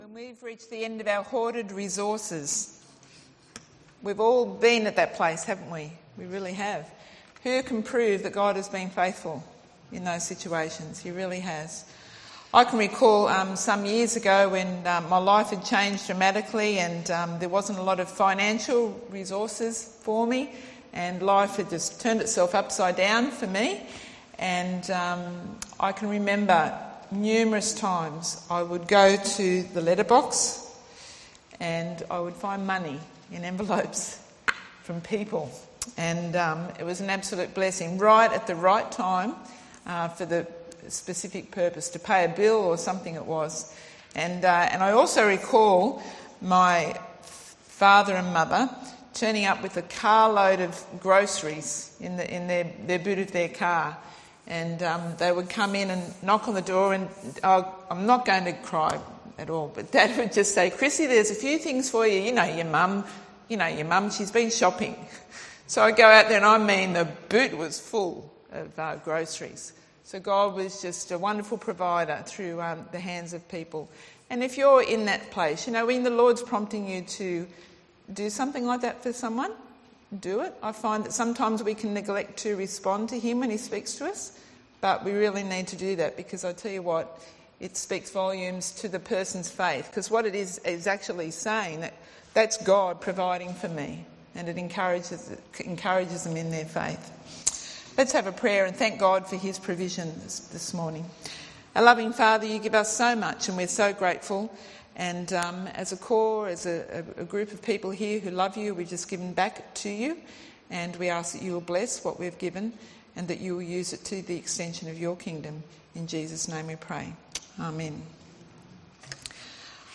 Sermon from the 10AM meeting at Newcastle Worship & Community Centre of The Salvation Army. Referenced to 2 Samuel 9:1-13.